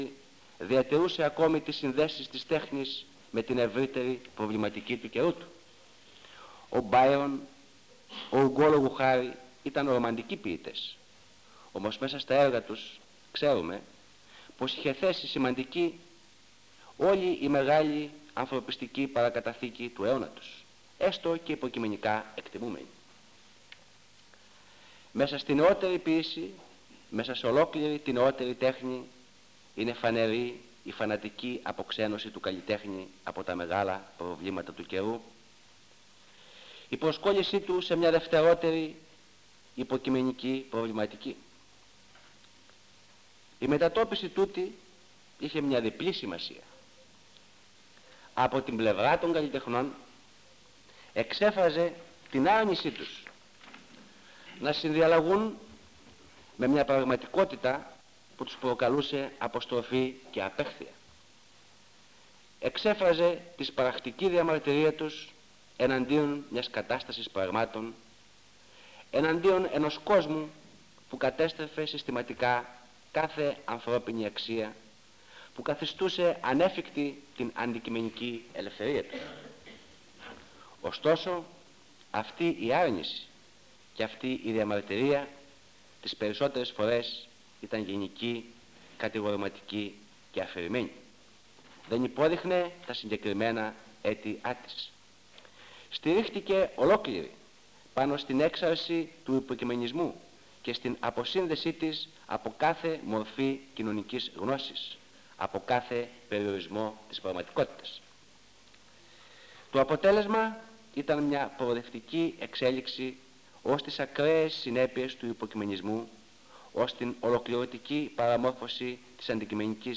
Εξειδίκευση τύπου : Εκδήλωση
Περιγραφή: Κύκλος Μαθημάτων με γενικό Θέμα "Σύγχρονοι Νεοέλληνες Ποιητές"